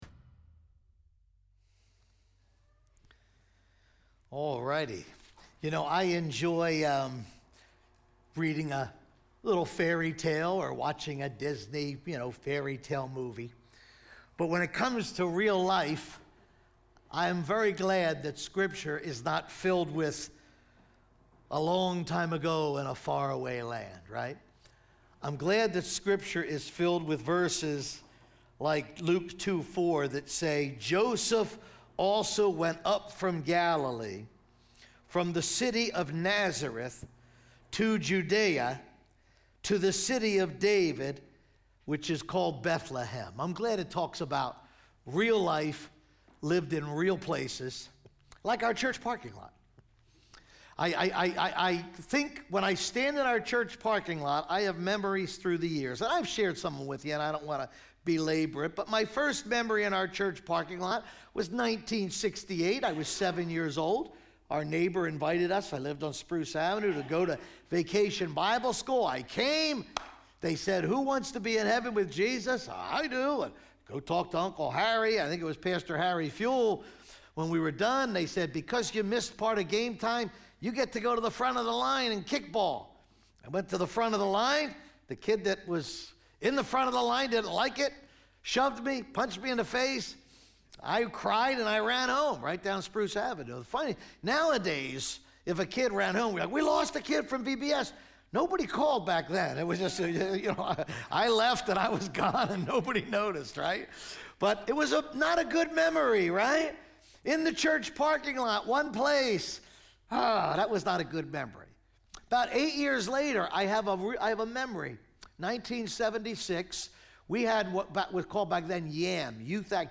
teaches from Micah 5:2-5.